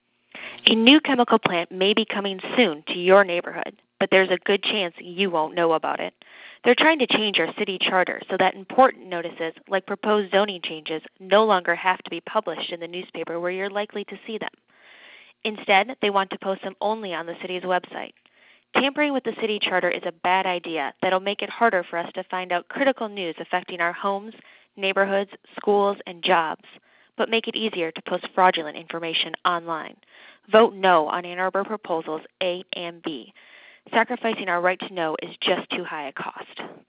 Out of the blue today, I got an automated call at my Ann Arbor home regarding ballot proposals A and B.  The call came with “unknown caller” CallerID, and there was no introduction.  The female-voiced call just started in saying that the city council might be trying to put “a chemical factory in our neighborhoods” without telling us about it.
Here’s a link to a .wav of the phone call advocating against the charter amendments on publishing: [